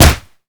kick_heavy_impact_03.wav